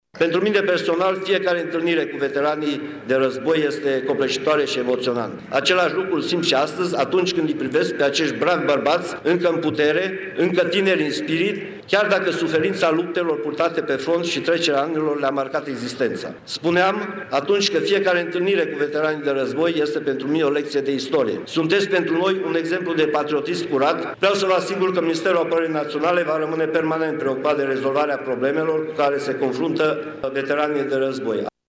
Ceremonia a avut loc în această dimineaţă în centrul municipiului Târgu-Mureş cu ocazia manifestărilor dedicate Zilei Independeţei şi Zilei Europei.
Ministrul Apărării Naţionale, Mircea Duşa, a ţinut să sublinieze în discursul susţinut cu această ocazie sprijinul pe care statul trebuie să-l acorde celor care au luptat în Cel de-al Doilea Război Mondial: